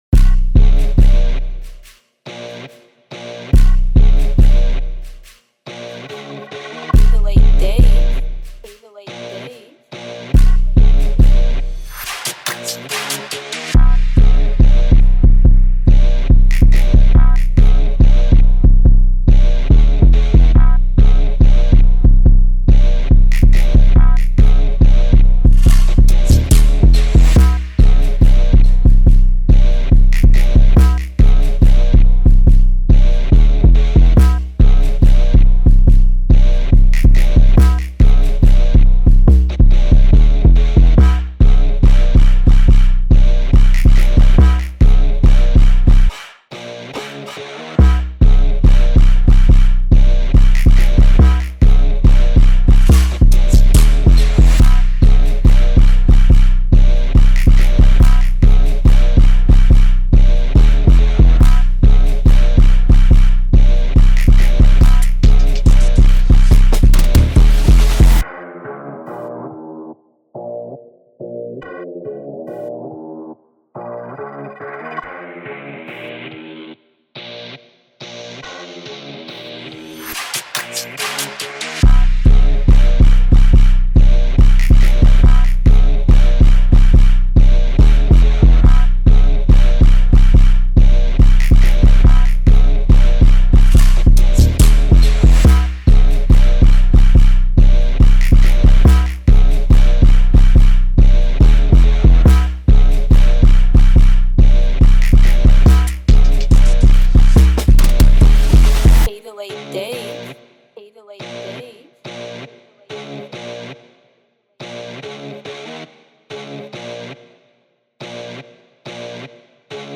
This is the official instrumental